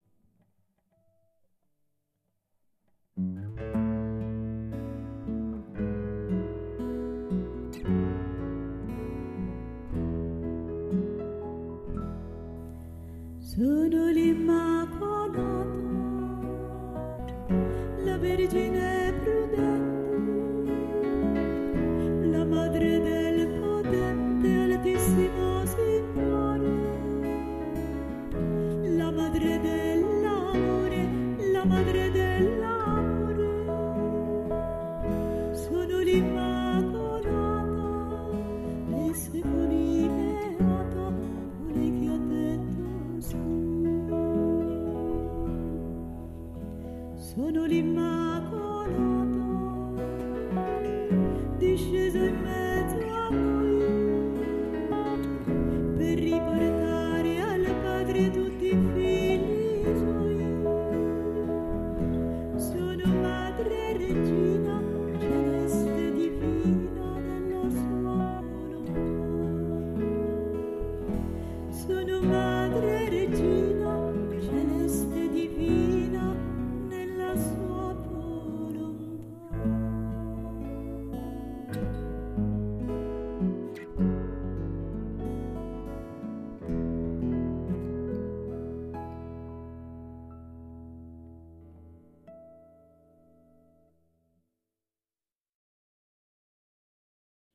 Sono l’Immacolata canto mp3